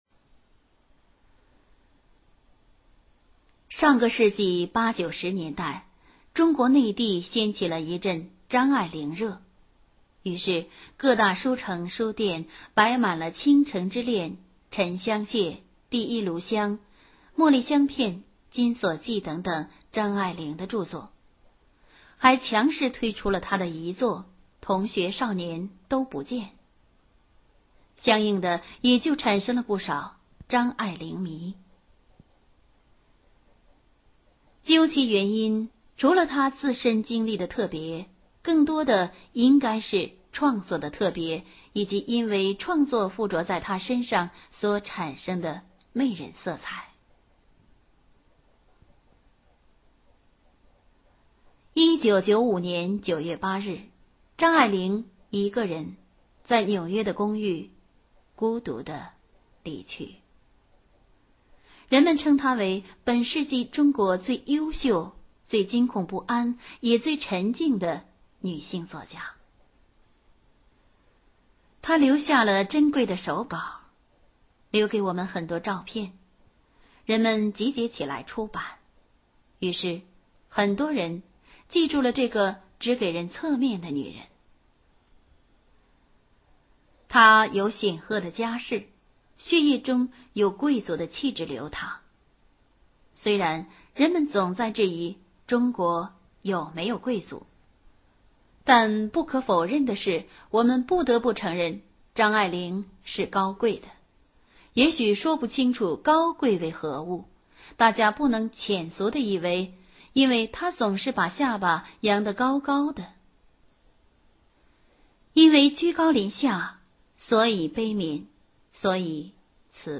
【有声文学】《一生难忘的30张面孔》